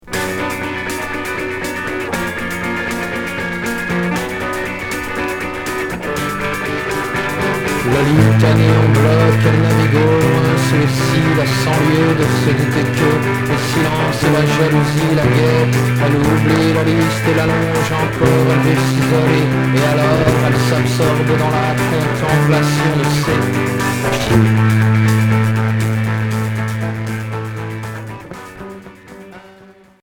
Rock Neuvième 45t r etour à l'accueil